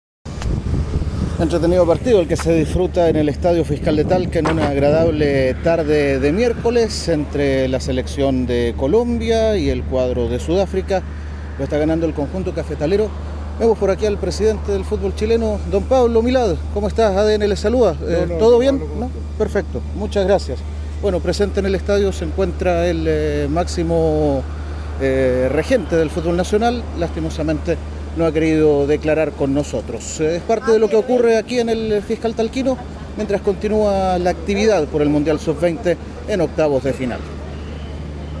En su llegada al Estadio Fiscal de Talca, ADN Deportes intentó abordar al presidente de la ANFP, quien se negó a dar declaraciones.
La respuesta de Pablo Milad en su llegada al Estadio Fiscal de Talca